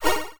Magic1.wav